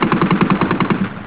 Biblioteca de sons » Sons » Sons de guerra
metralhadora3.au